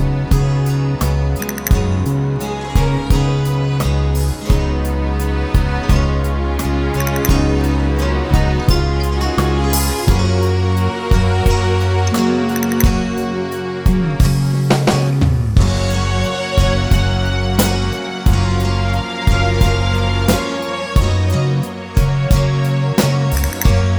Full Live Version Pop (1960s) 5:34 Buy £1.50